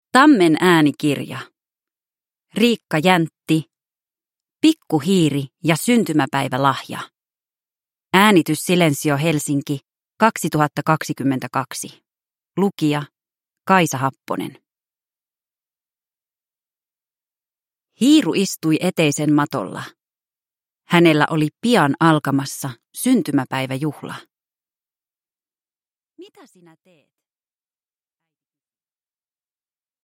Pikku hiiri ja syntymäpäivälahja – Ljudbok – Laddas ner